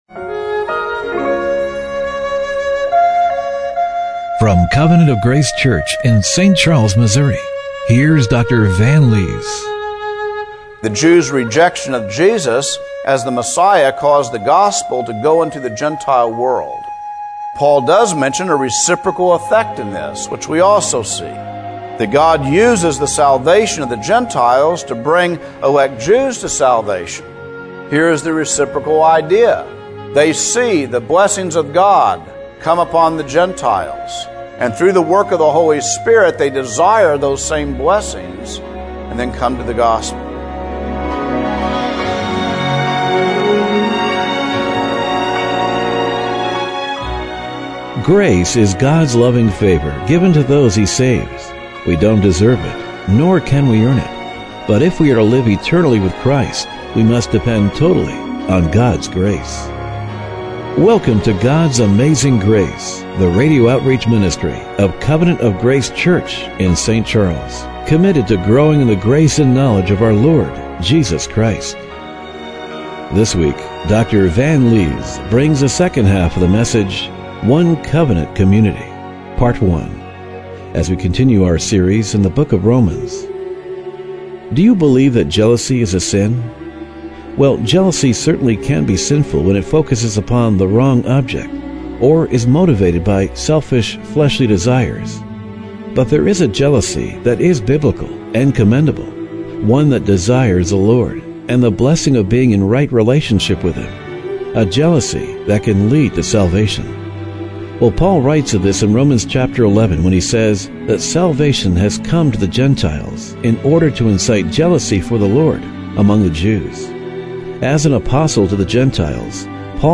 Romans 11:11-15 Service Type: Radio Broadcast Do you recognize and embrace Jesus Christ